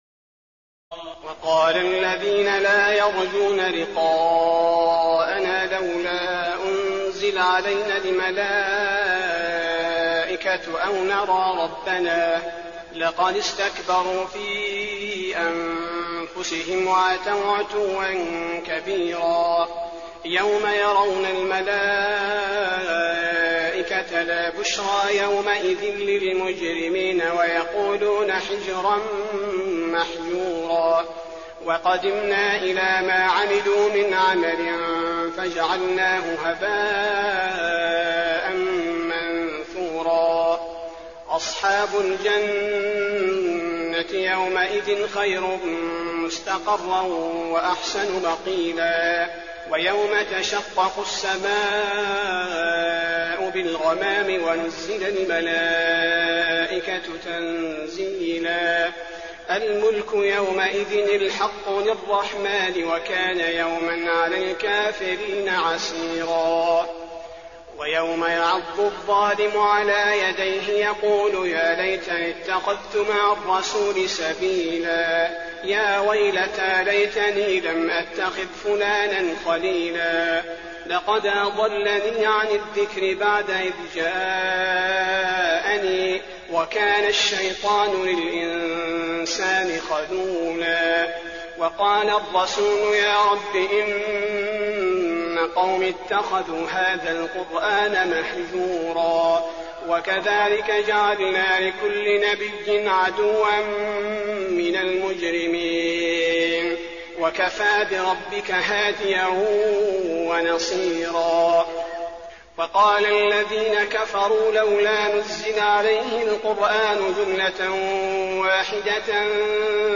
تراويح الليلة الثامنة عشر رمضان 1422هـ من سورتي الفرقان (21-77) و الشعراء (1-104) Taraweeh 18 st night Ramadan 1422H from Surah Al-Furqaan and Ash-Shu'araa > تراويح الحرم النبوي عام 1422 🕌 > التراويح - تلاوات الحرمين